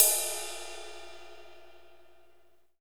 Index of /90_sSampleCDs/Northstar - Drumscapes Roland/DRM_AC Lite Jazz/CYM_A_C Cymbalsx